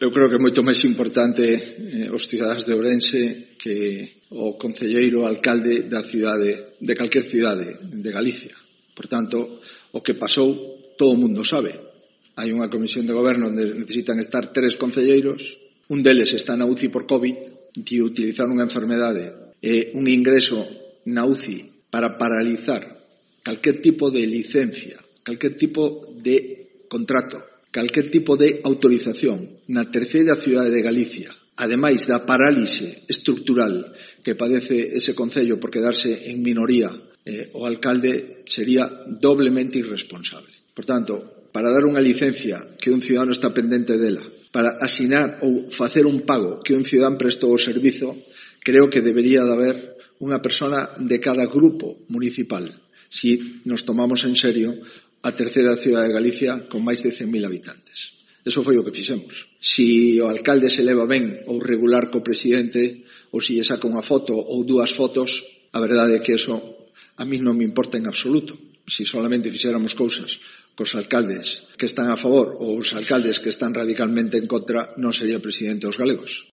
Declaraciones de Núñez Feijóo sobre la incorporación del PP a la Xunta de Goberno del Concello de Ourense